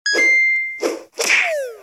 Kategorien: Spiele